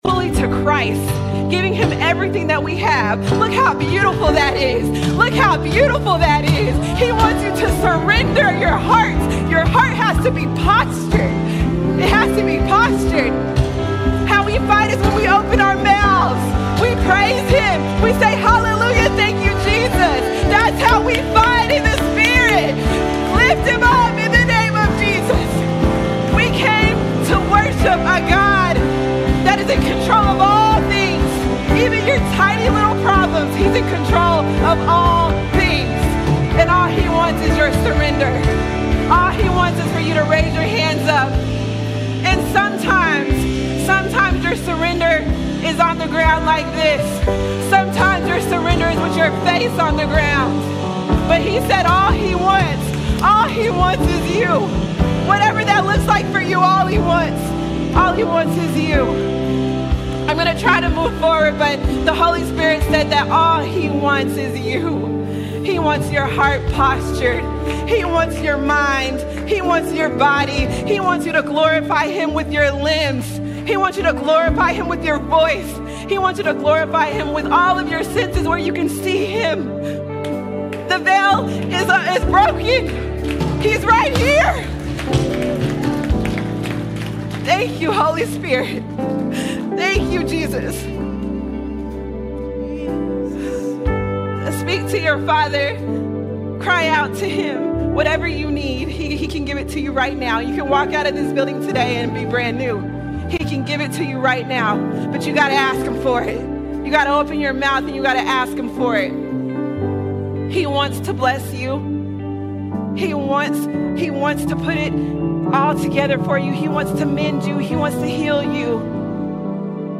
23 March 2026 Series: Sunday Sermons All Sermons Renewing The Mind Renewing The Mind Our minds shape our lives—but are they aligned with God?